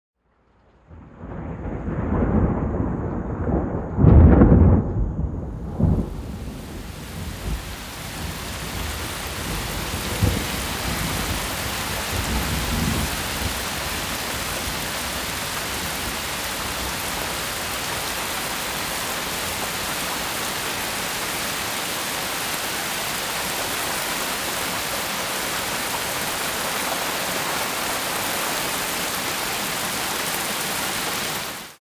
Гром, первые капли дождя